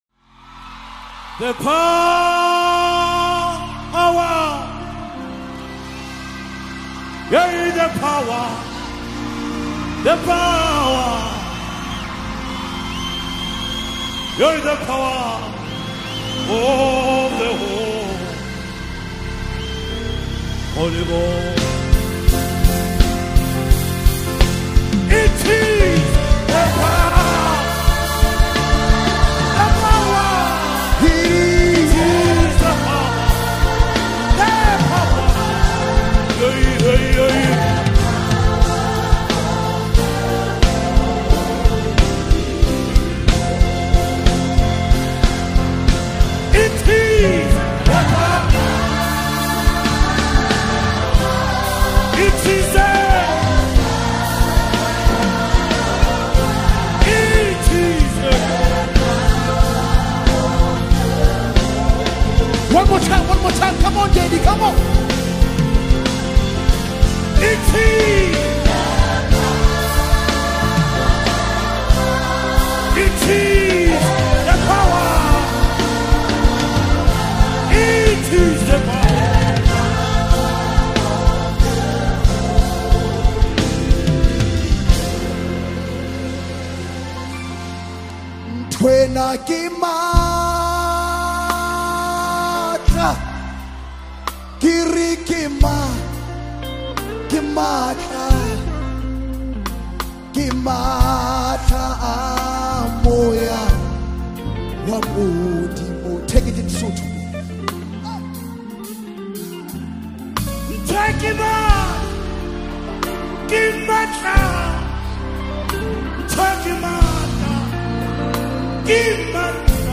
Anointed live performance with deep spiritual impact
📅 Category: South African Most Loved Worship Song